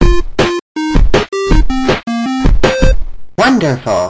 penny haze and ivy voice lines